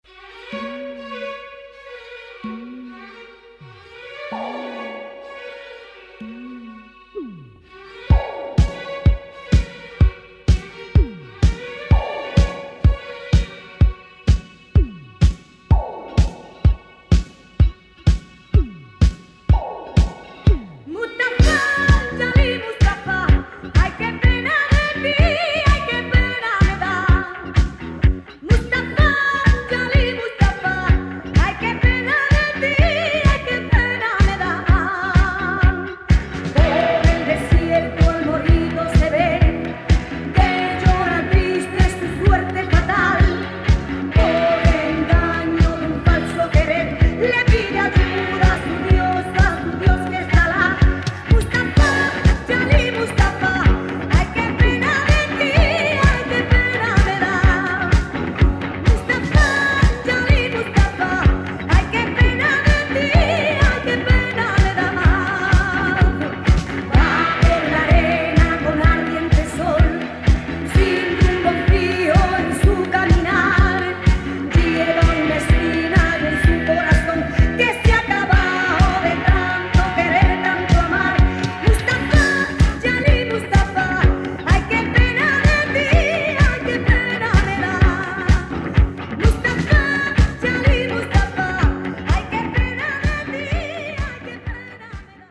Gypsy rumba disco with Arabic influences